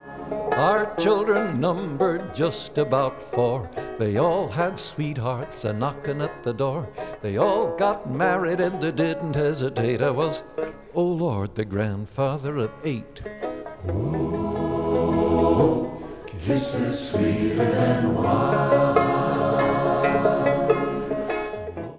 voice, banjo
chorus
He'd played A major 7th chords, but sang it in A minor.